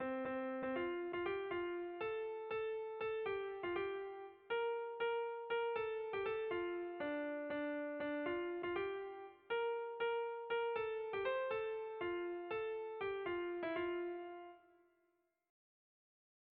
Erlijiozkoa
AITA AHALGUZTIDUNA izeneko doinuarekin batera kantatzeko da doinua; hau herriak eta bestea bakarlariak, txandaka kanta dezan alegia.
A-B-B2